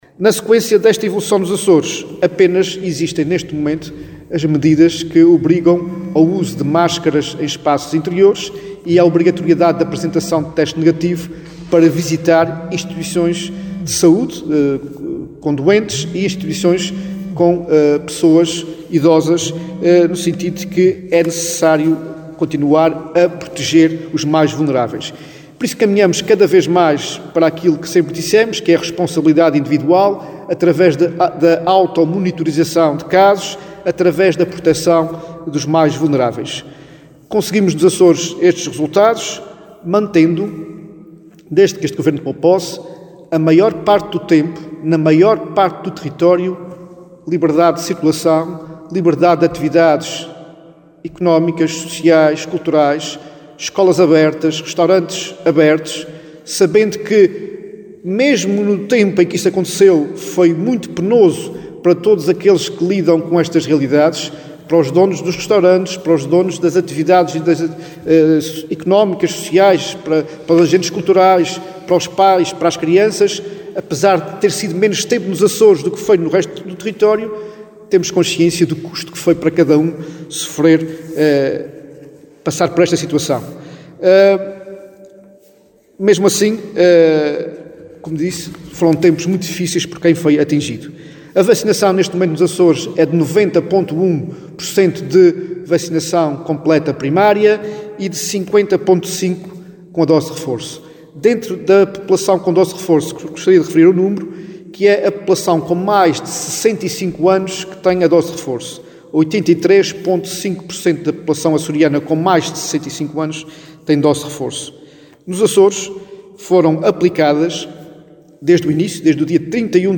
Em dois anos, a covid-19 obrigou a um investimento na ordem dos 80 milhões de euros, revelou hoje o Secretário Regional da Saúde e Desporto, numa conferência de imprensa, em Angra do Heroísmo, destinada a fazer o balanço dos dois anos de pandemia nos Açores.